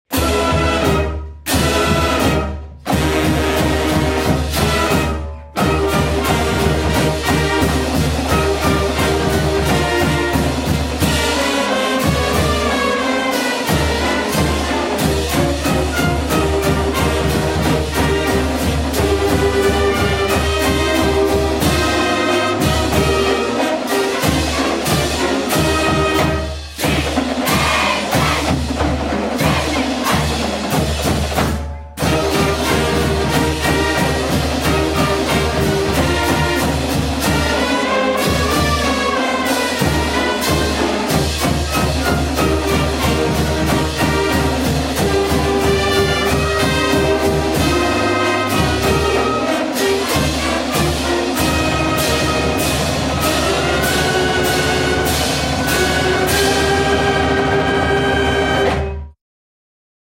chapin_fight_song 360p.mp3